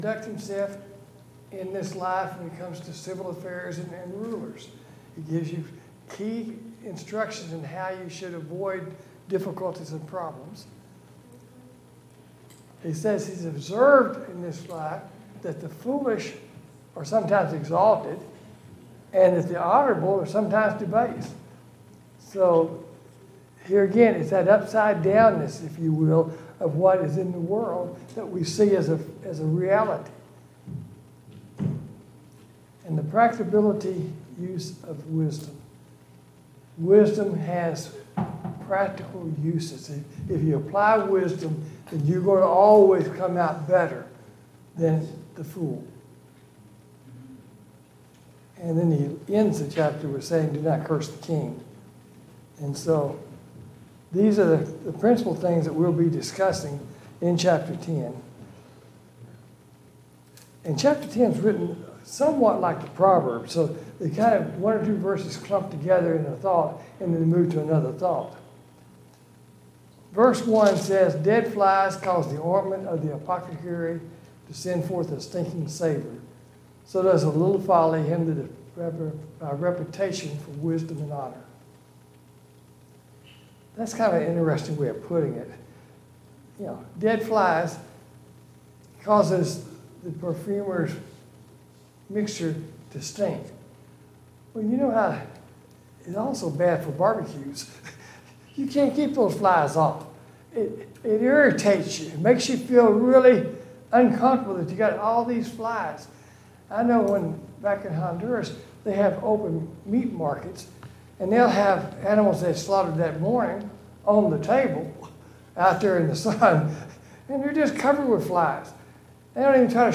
A Study of Ecclesiastes Passage: Ecclesiastes 10 Service Type: Sunday Morning Bible Class « 42.